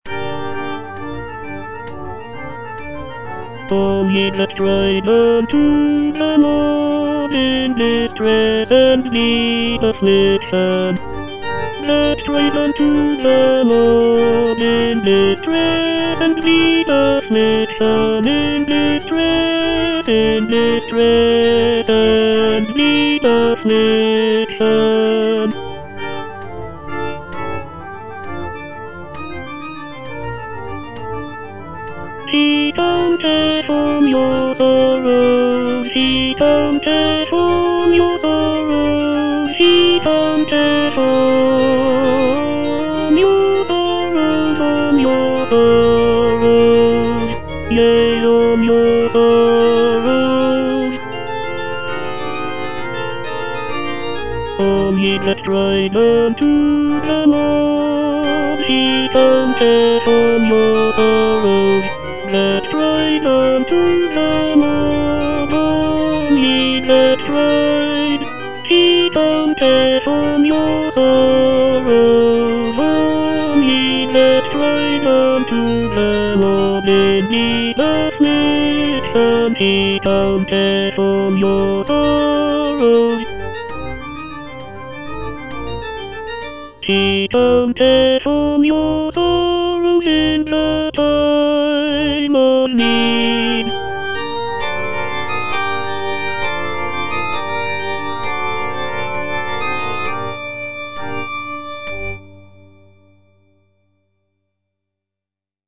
F. MENDELSSOHN - HYMN OF PRAISE (ENGLISH VERSION OF "LOBGESANG") All ye that cried unto the Lord - Tenor (Voice with metronome) Ads stop:
"Hymn of Praise" is a choral symphony composed by Felix Mendelssohn in 1840.
The piece features a large orchestra, chorus, and soloists, and is divided into three parts that include a mixture of orchestral and vocal movements.